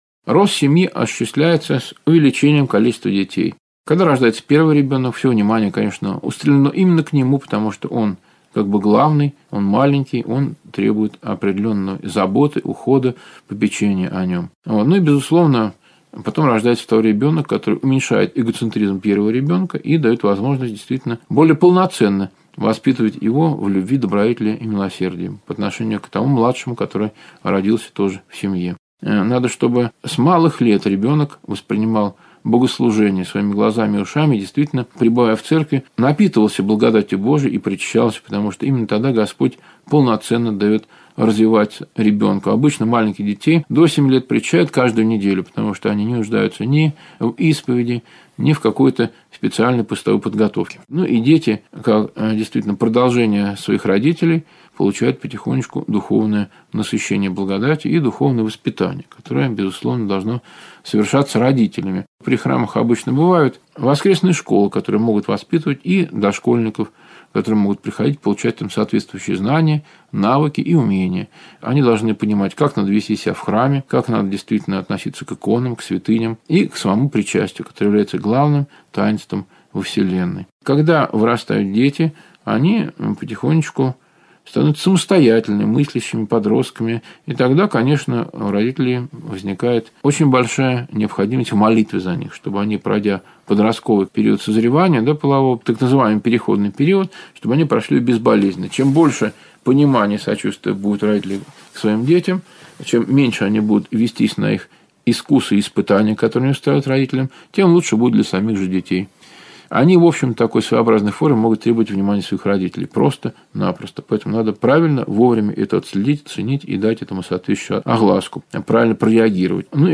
Аудиокнига: Нравственность, брак, семья